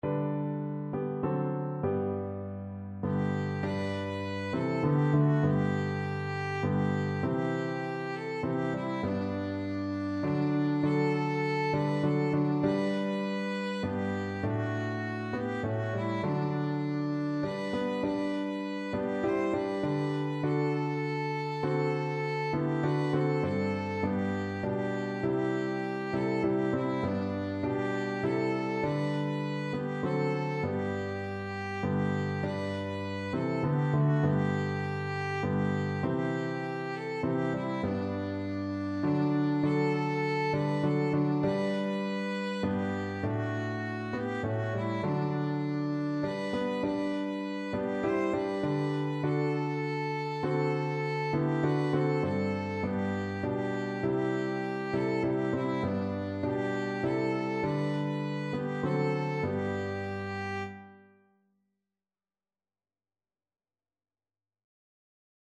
3/4 (View more 3/4 Music)
Classical (View more Classical Violin Music)